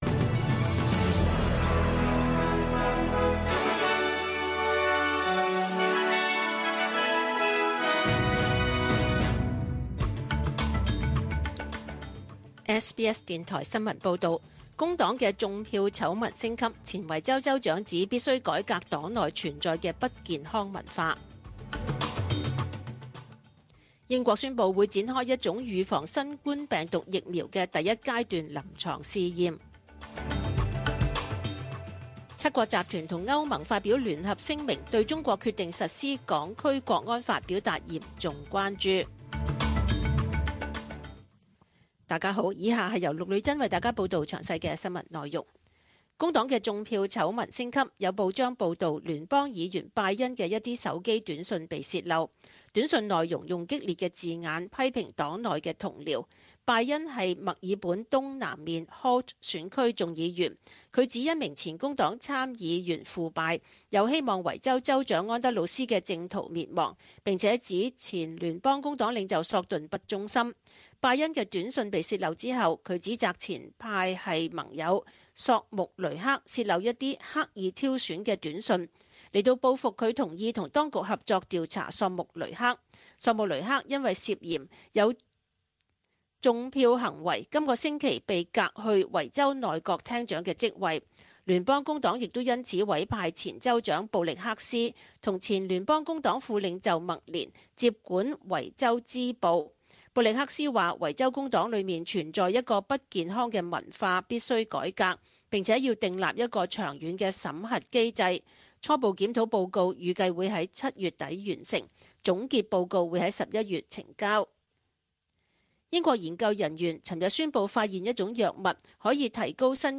SBS中文新闻 （六月十八日）
请收听本台为大家准备的详尽早晨新闻